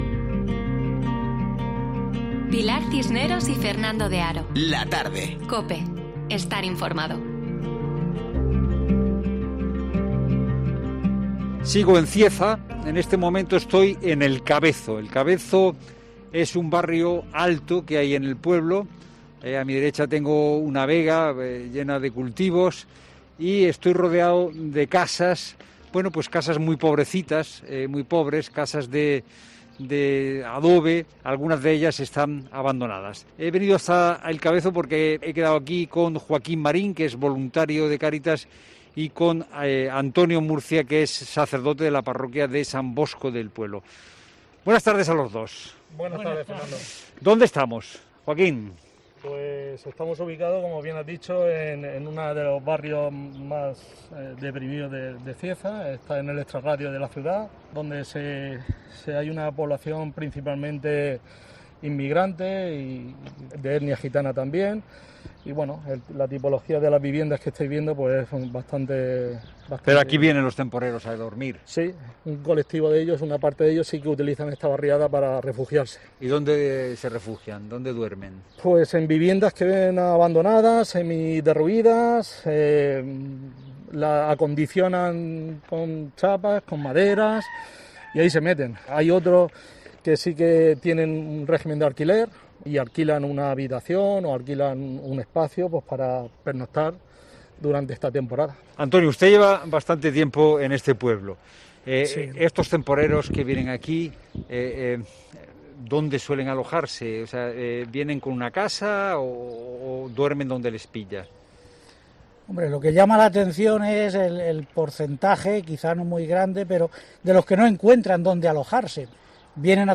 AUDIO: Una parte de la población que acude a Cieza a trabajar, indicaba un voluntario de Cáritas en 'La Tarde', también se refugia en cuevas cercanas...